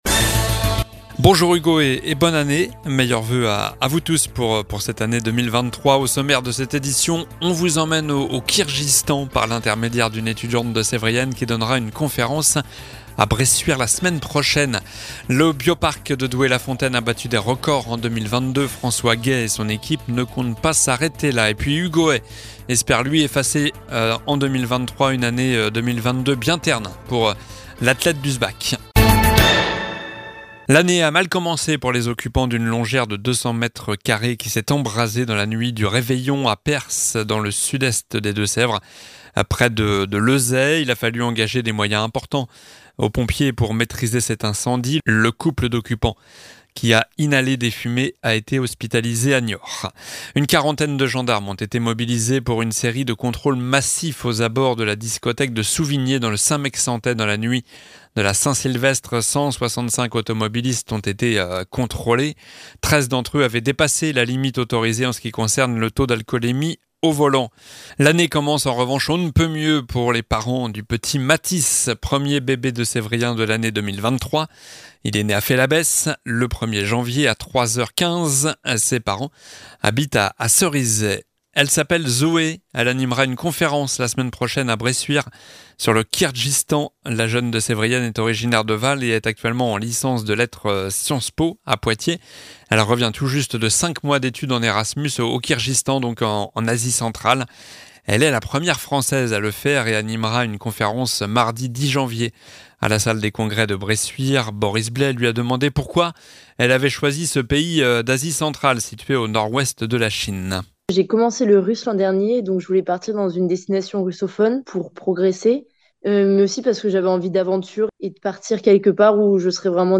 Journal du lundi 02 janvier (midi)